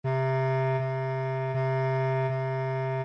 Piano (Corda percutida) | Orquestra de cARTón (ODE5)